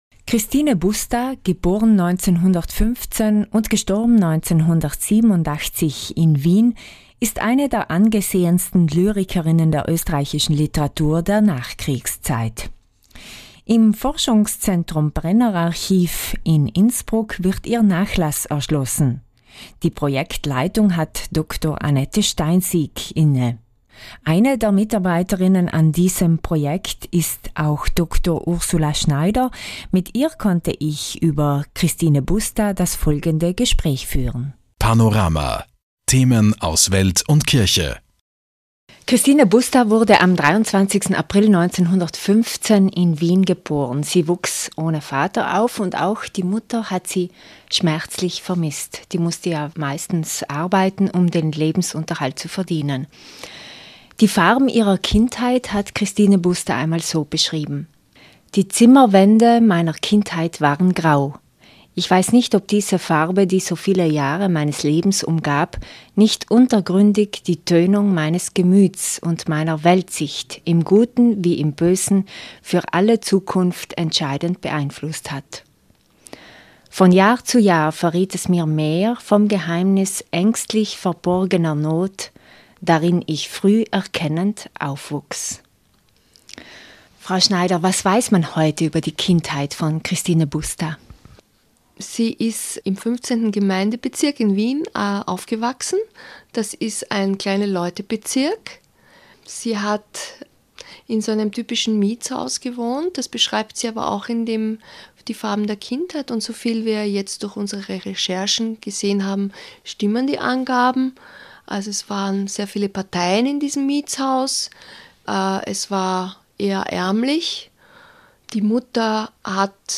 bustainterview.mp3